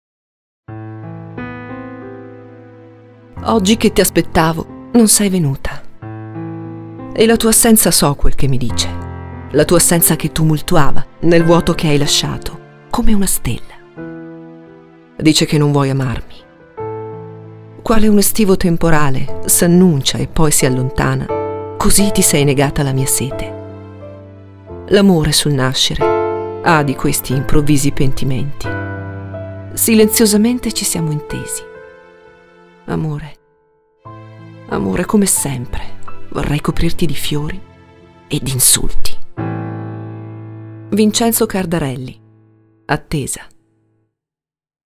Audio Poesia